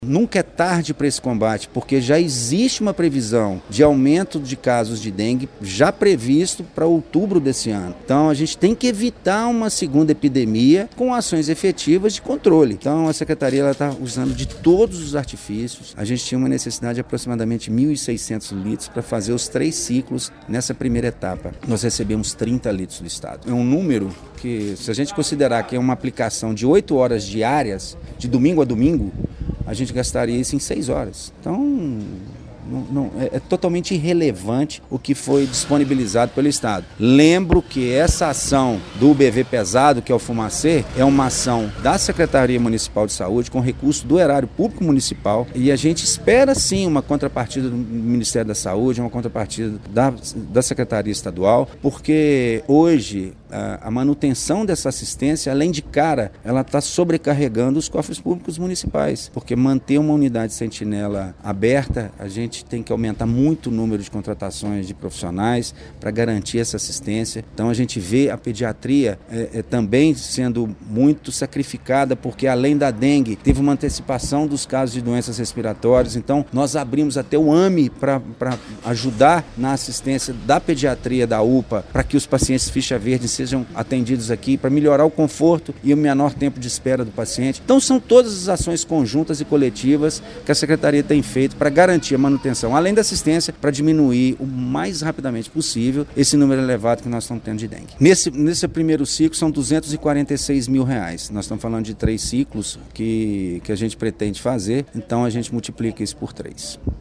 O secretário municipal de Saúde, Wagner Magesty, destaca ações realizadas para conter a proliferação do mosquito Aedes aegypti, em meio a maior epidemia de Dengue já registrada no hemisfério sul, desde a ação dos agentes em campo, passando pelo tratamento e constatação que os casos ainda não apresentam tendência de queda.